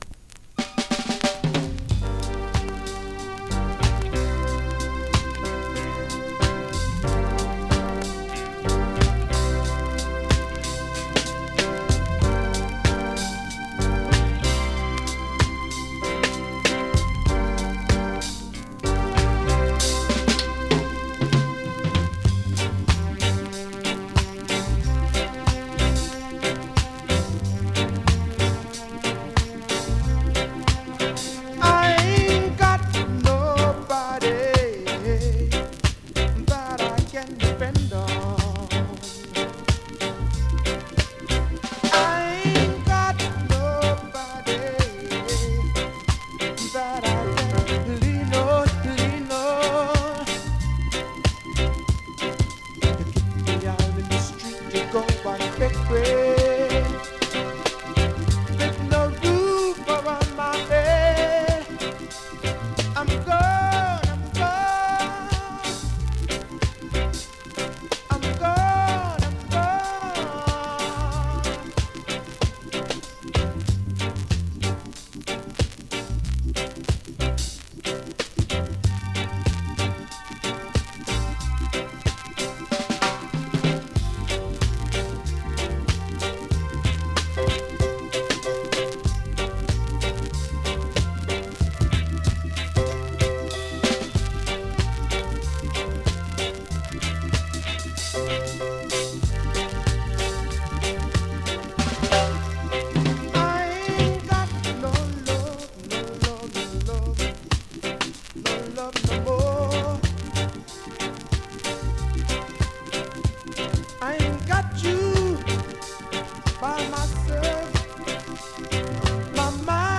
NEW IN!SKA〜REGGAE
スリキズ、ノイズかなり少なめの